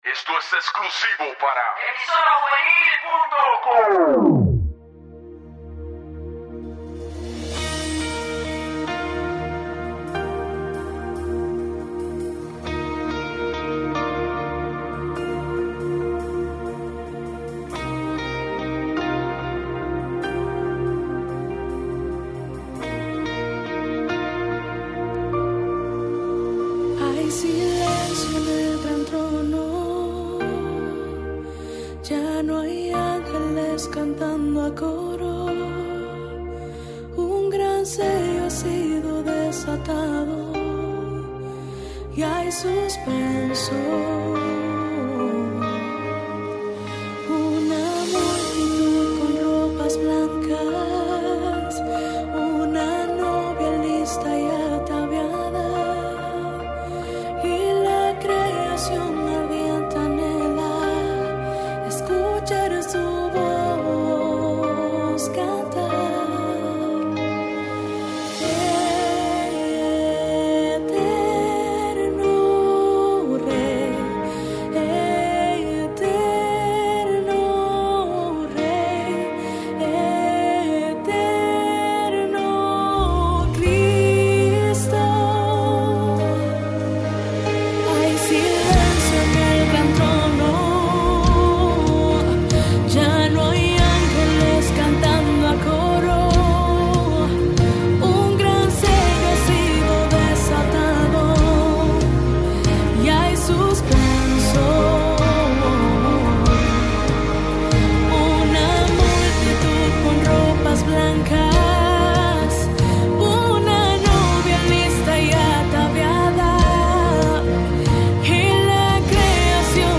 Música Cristiana